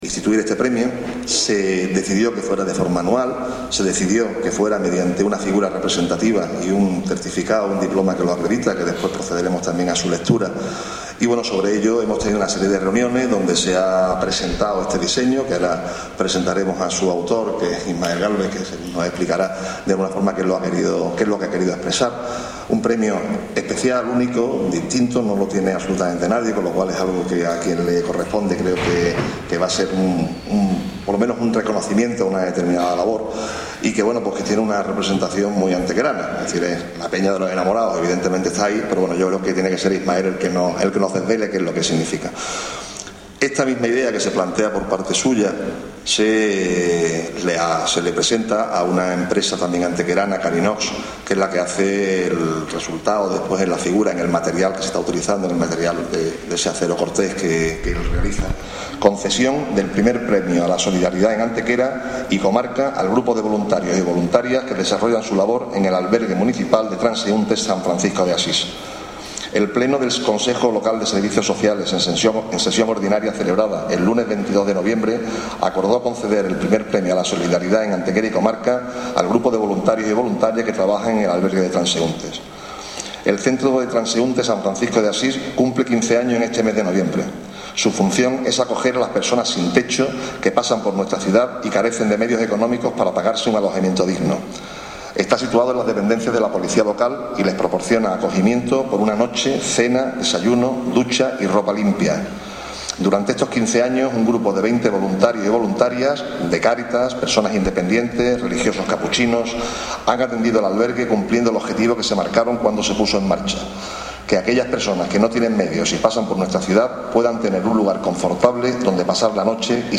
Entregó el premio el alcalde de la ciudad, Ricardo Millán, quien estuvo acompañado por la concejala de Derechos Sociales, Dolores García, y otros representantes de la Corporación Municipal.
Cortes de voz
Audio: alcalde   2382.04 kb  Formato:  mp3